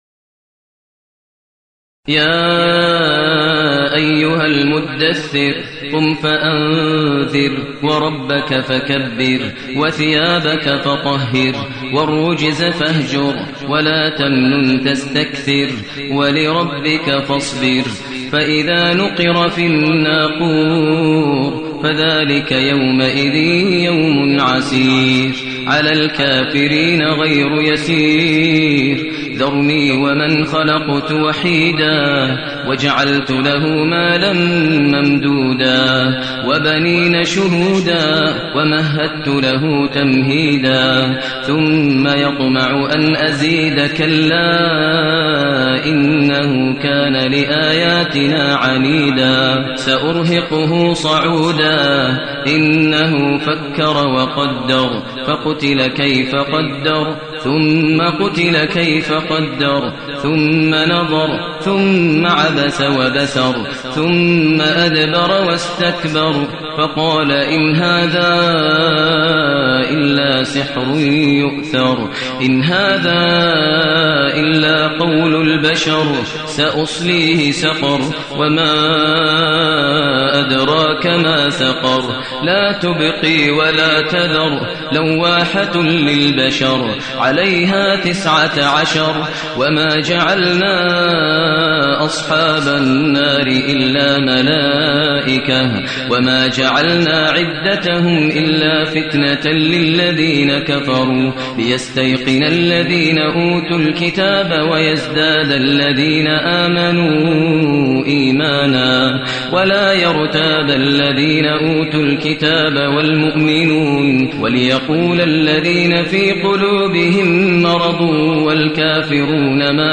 المكان: المسجد الحرام الشيخ: فضيلة الشيخ ماهر المعيقلي فضيلة الشيخ ماهر المعيقلي المدثر